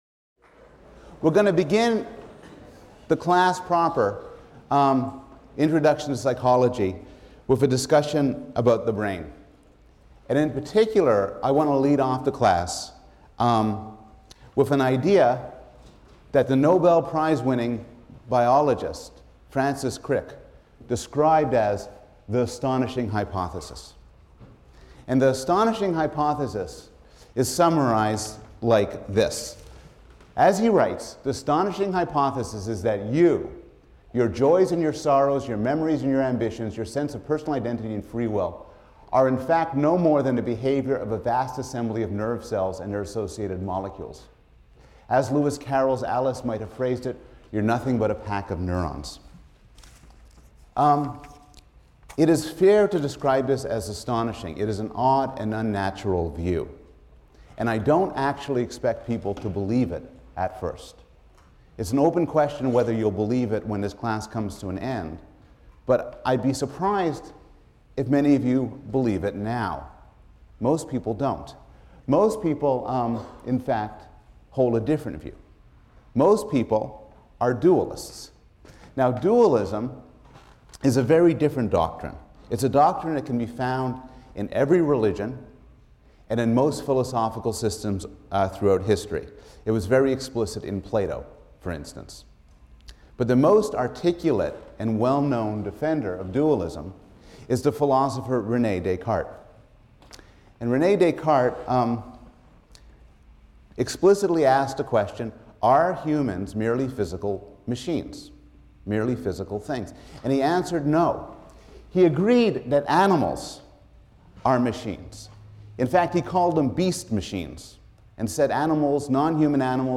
PSYC 110 - Lecture 2 - Foundations: This Is Your Brain | Open Yale Courses